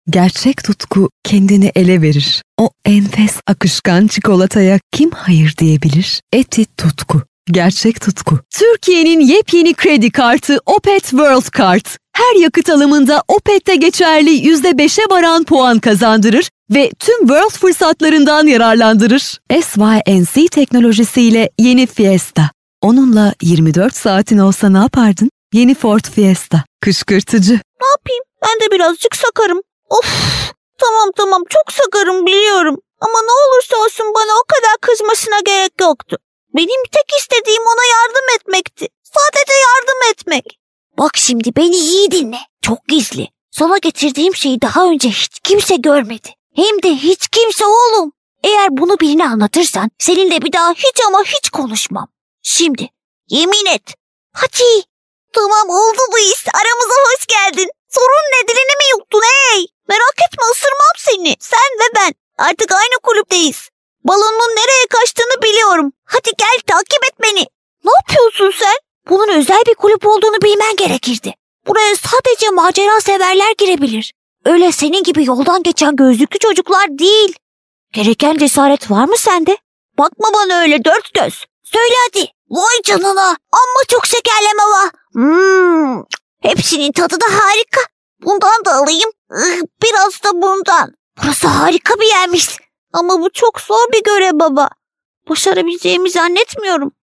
Hello I am professional Turkish voice artist.
I have my own recordig studio.
Kein Dialekt
Sprechprobe: Industrie (Muttersprache):